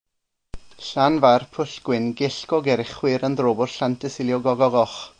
Want to have a go at pronouncing it?